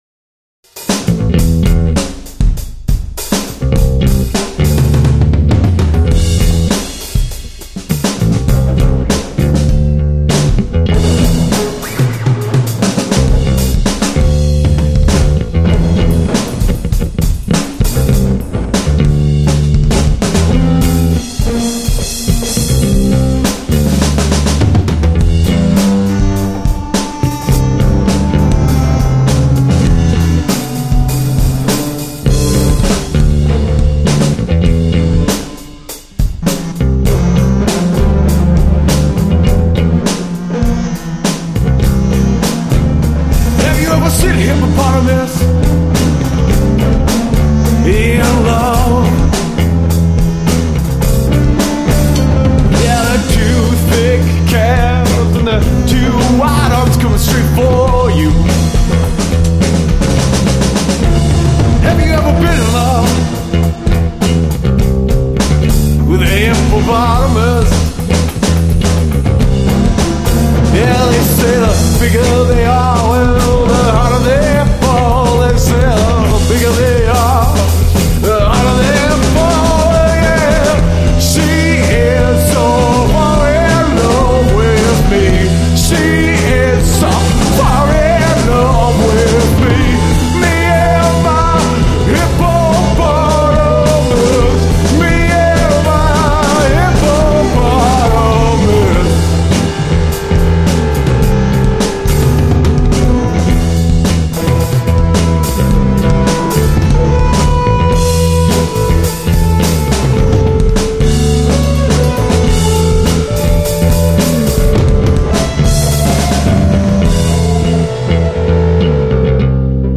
Avantgarde
Main Vocals
Drums
Bass Guitar
Synthesizer